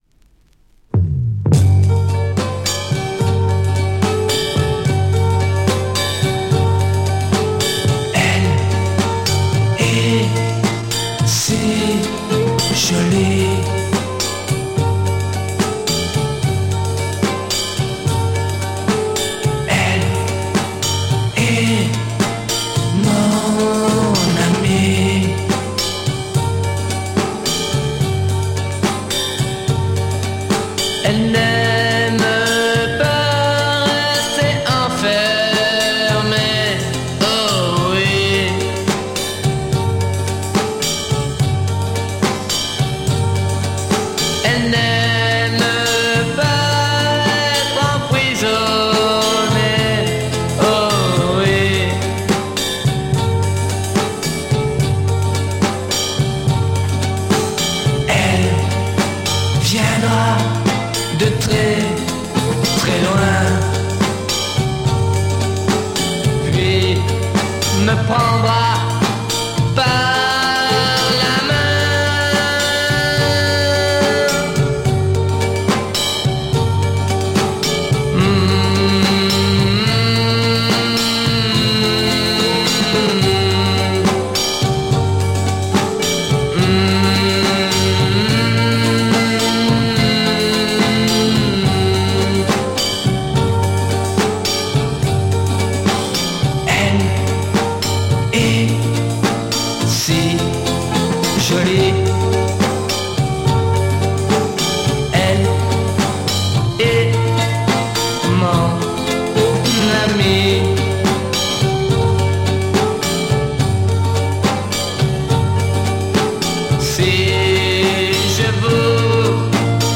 Play a decent garage folk punk sound!!